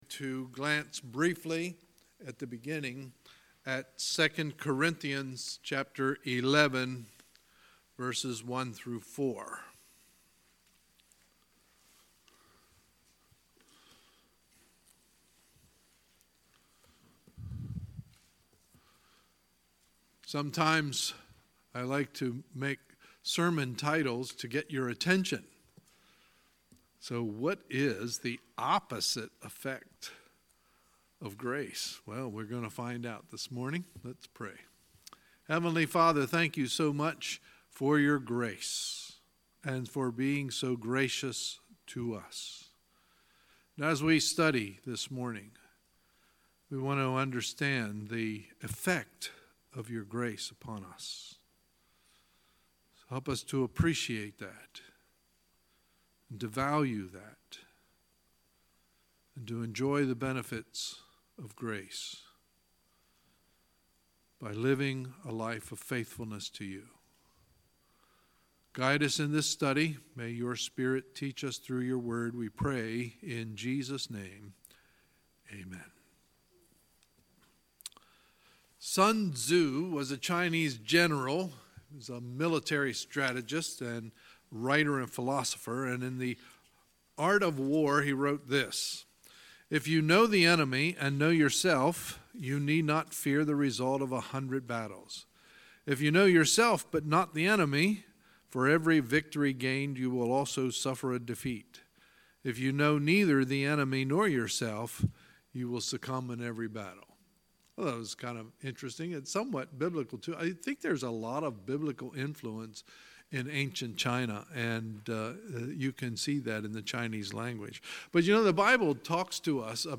Sunday, October 14, 2018 – Sunday Morning Service